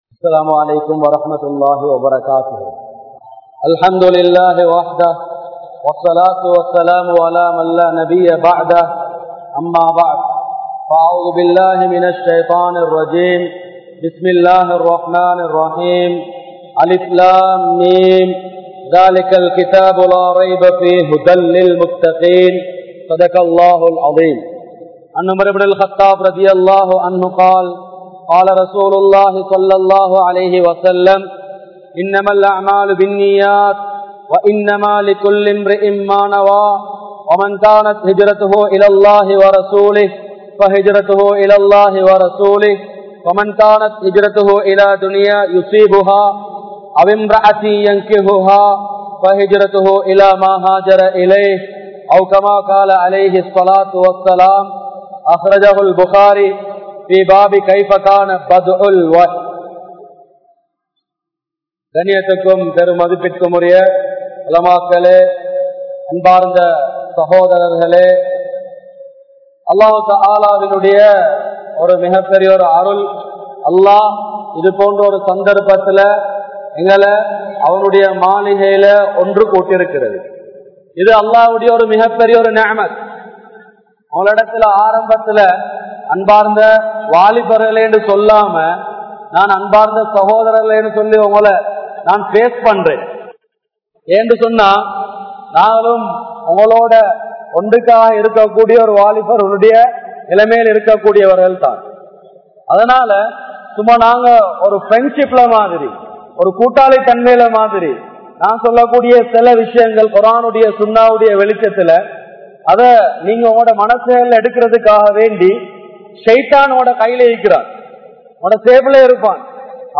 Muslim Vaalifarhalin Panpuhal (முஸ்லிம் வாலிபர்களின் பண்புகள்) | Audio Bayans | All Ceylon Muslim Youth Community | Addalaichenai